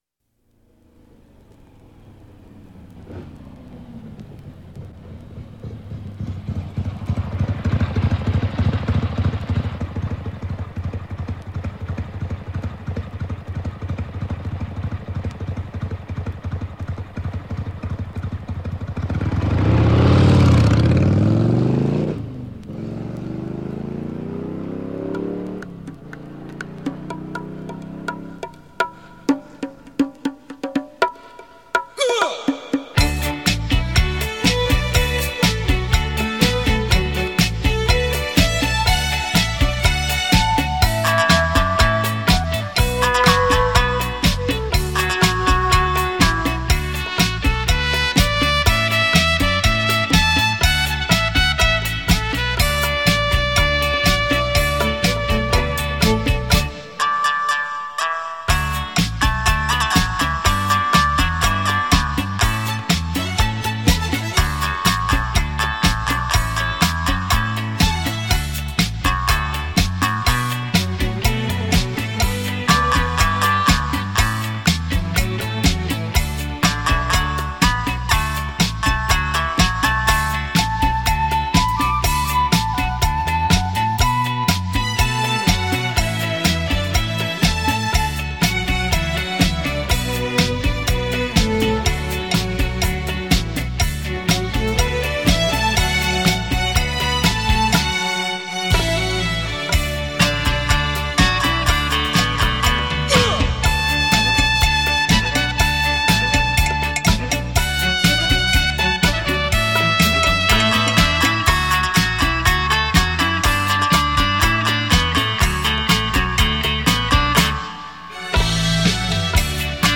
超重音 X-BASS 数位环绕音效
道中篇 水晶鼓与女合声的魅惑